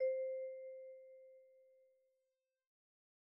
IBI Bell C4.wav